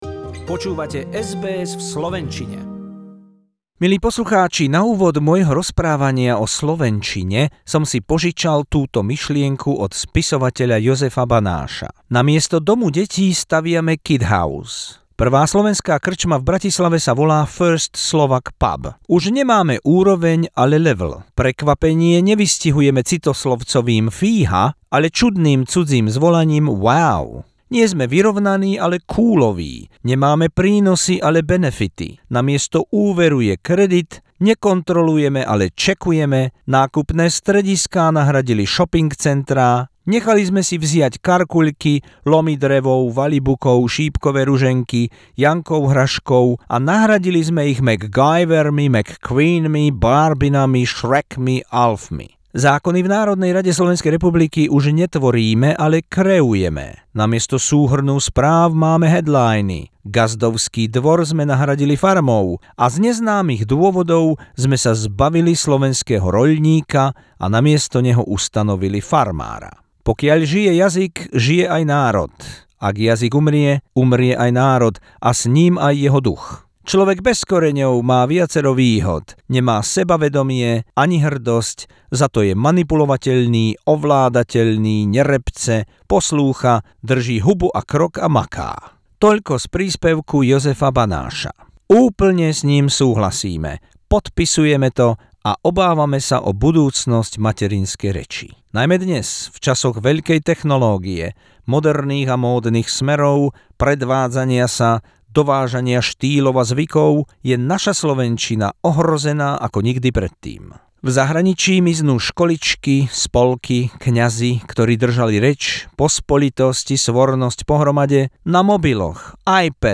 Pohľad na osud nášho materinského jazyka doma i v zahraničí, na jeho krehkosť, ale aj silu, slabú ochranu, ale aj stáročia udržiavanú podobu, na nebezpečenstvá cudzích vplyvov, ale aj ľubozvučnosť v hlase majstrov prednesu. V závere unikátne úryvky nebohých velikánov divadla.